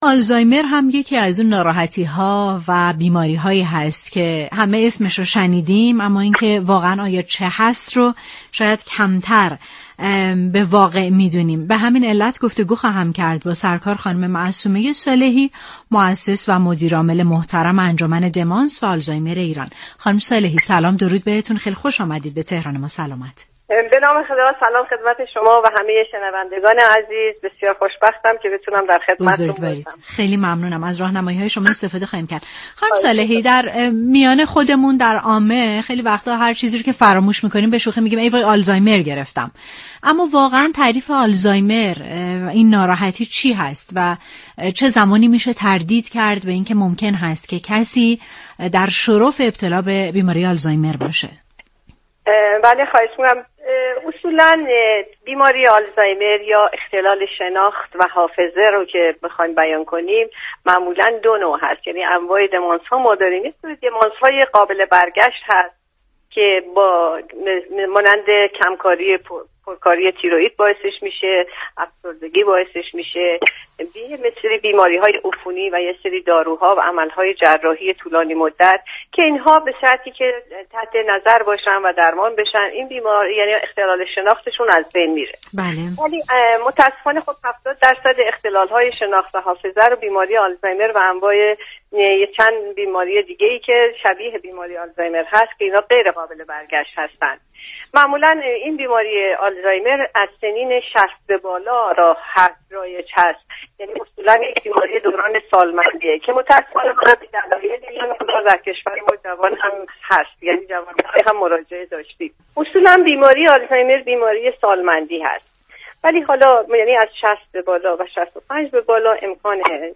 در گفت و گو با «تهران ما، سلامت»